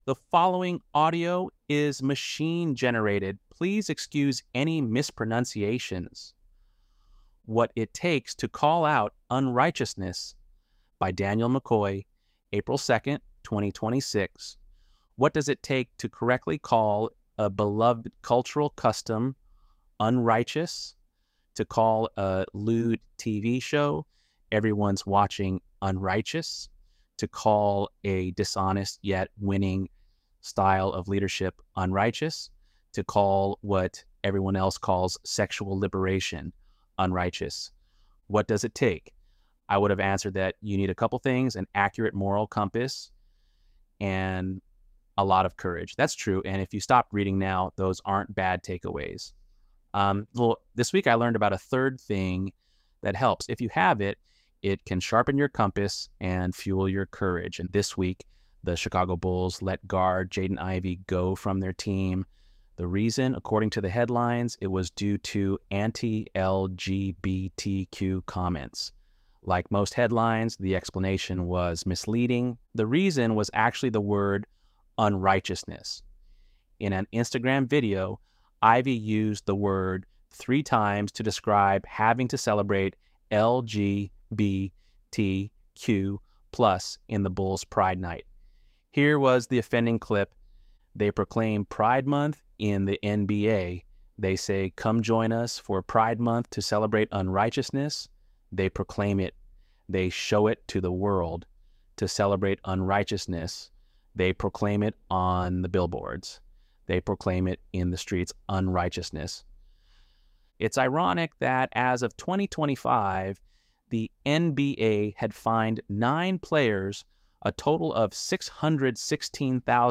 ElevenLabs_Untitled_project-50.mp3